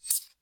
weapon audio
swordUnsheath1.ogg